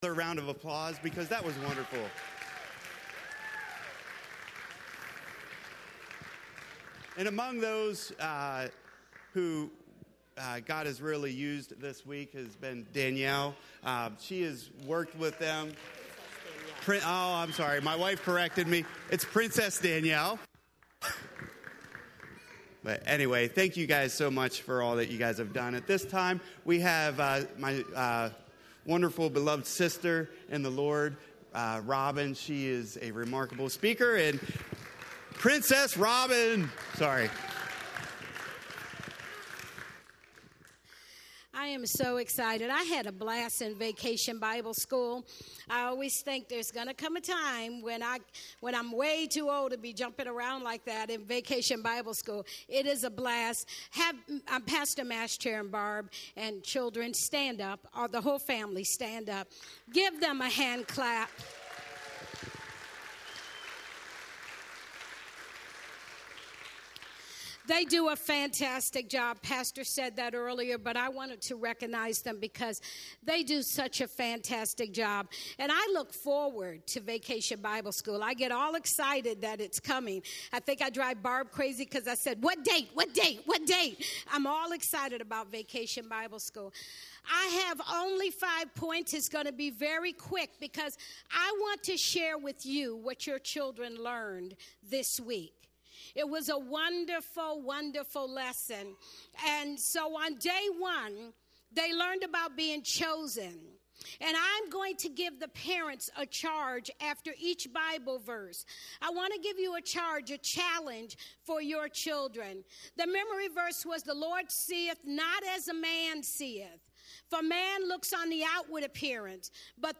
Sunday morning sermon